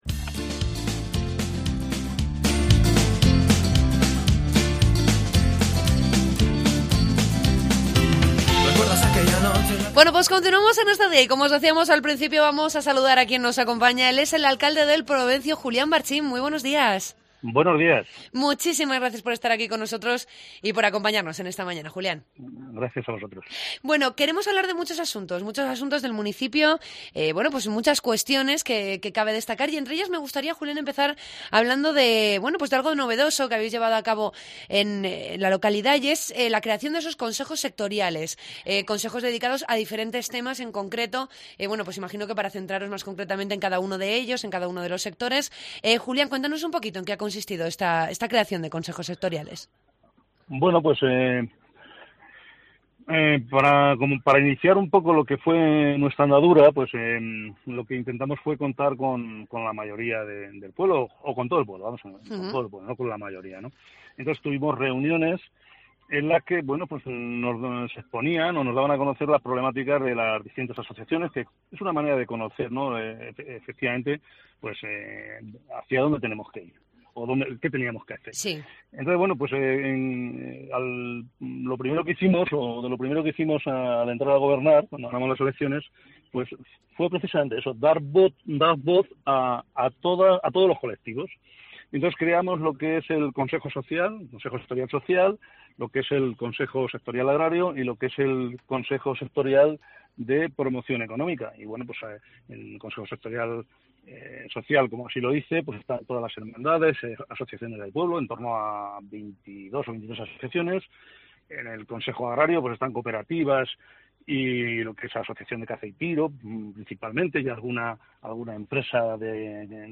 Entrevistamos al alcalde de El Provencio, Julián Barchín.